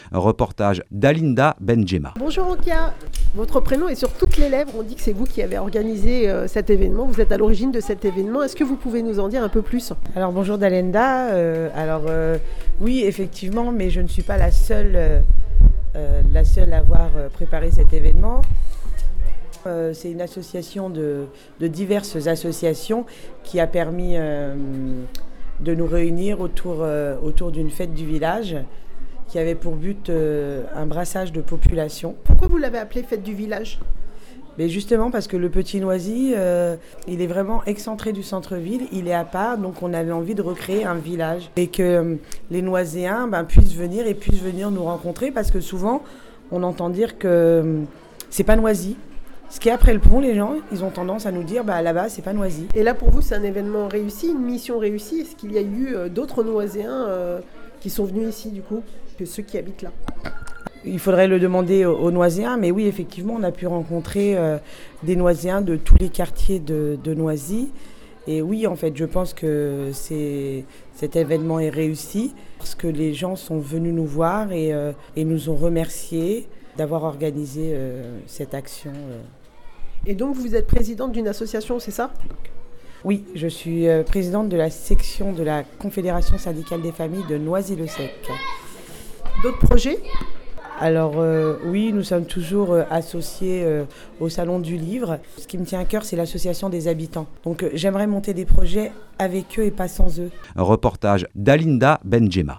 lors du week-end de la fête de village dans le quartier Alsace-Lorraine.